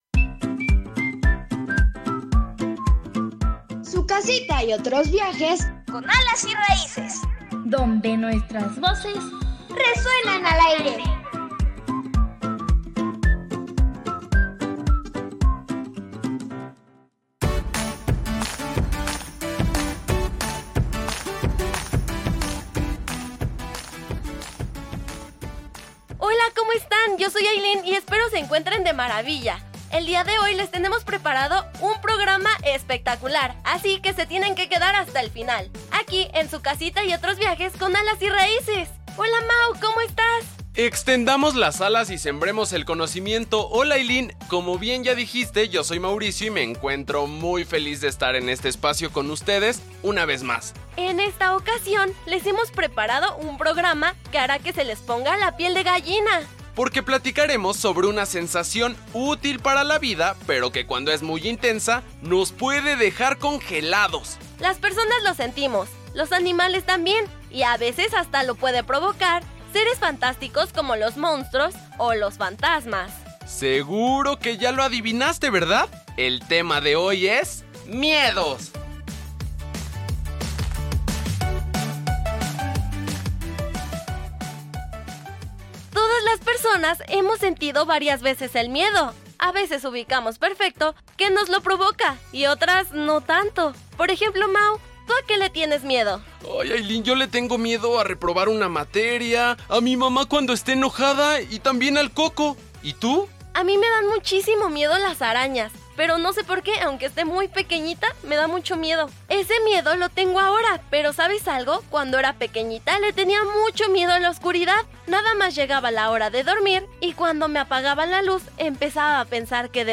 La pregunta: Niñas y niños contestan a las preguntas ¿Para qué sirve el miedo?, ¿Se puede tener miedo a algo pequeño?, ¿Qué haces cuando sientes miedo?
La recomendación: Serie radiofónica: Entre nahuales y brujas, disponible en el sitio de Alas y Raíces.